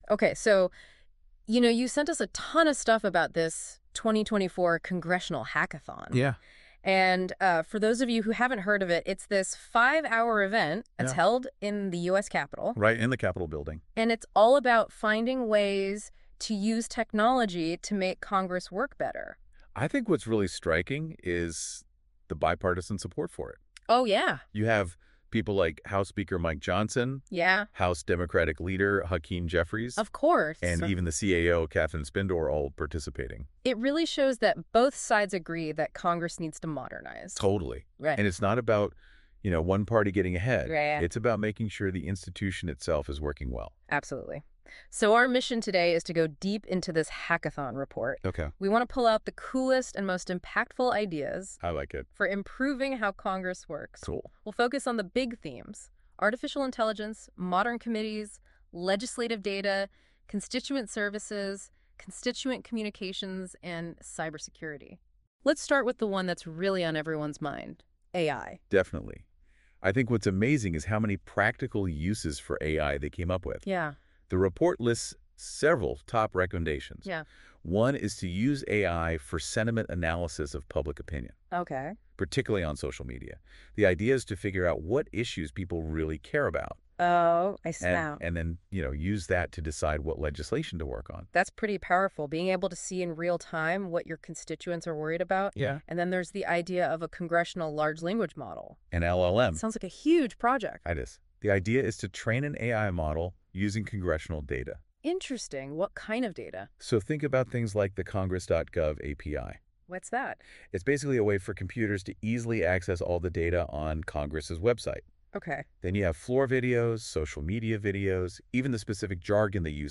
Listen to an AI-generated, 16-minute podcast-style recap of the event: